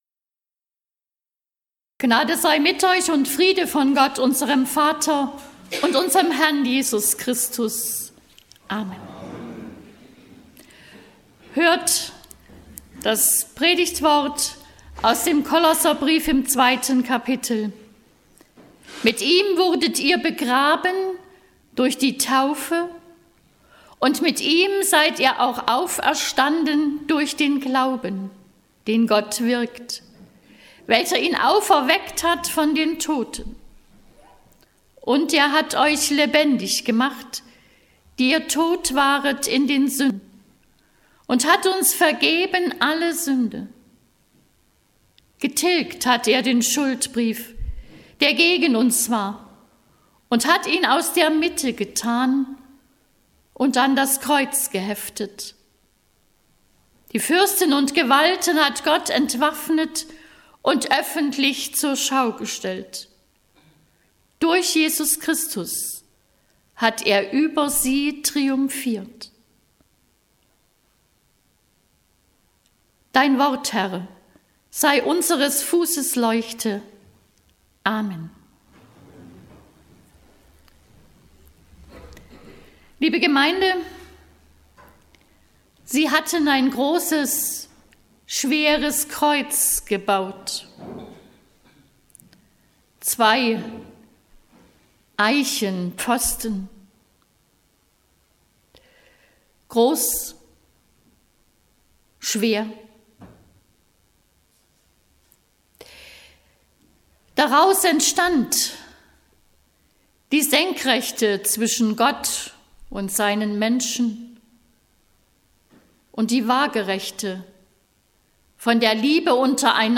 Predigt vom Sonntag Quasimodogeniti nachhören
Quasimodigeniti_Predigt.mp3